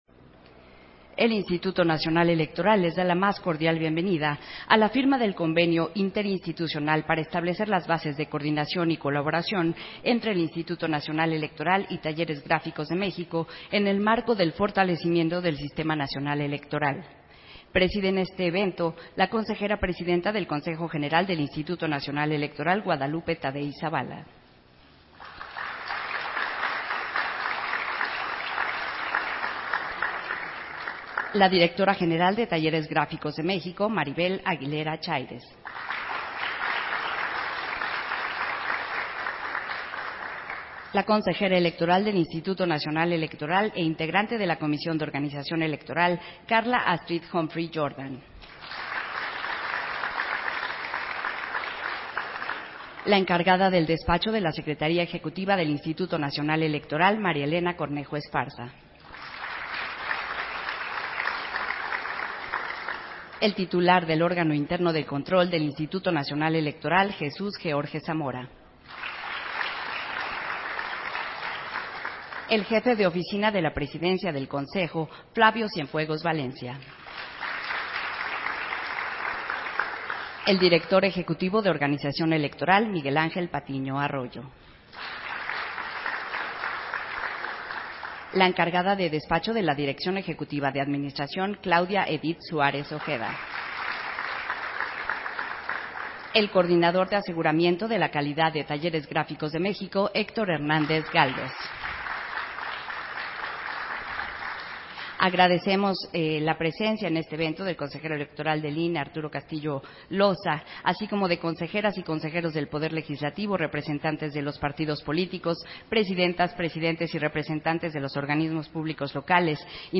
170823_AUDIO_FIRMA-DE-CONVENIO-INE-TALLERES-GRÁFICOS-DE-MÉXICO